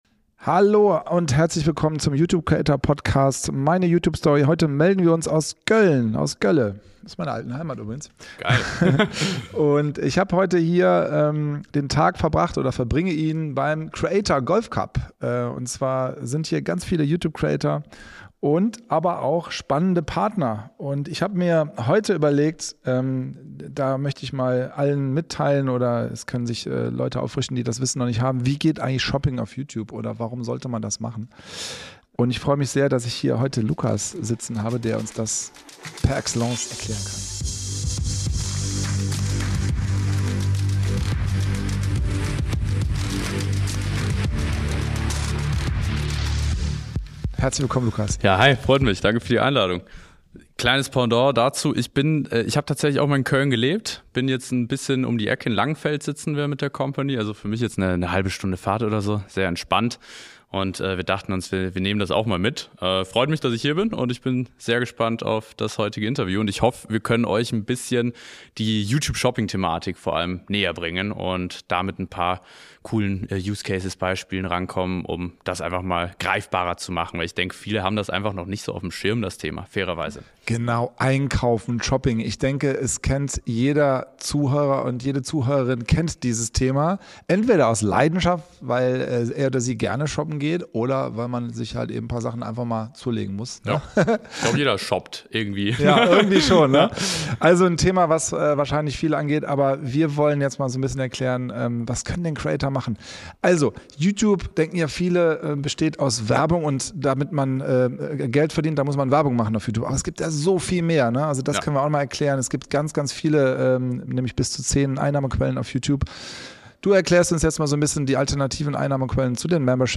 In dieser Episode des YouTube Creator Podcasts "Meine YouTube Story" melden wir uns direkt vom Creator Golfcup in Köln.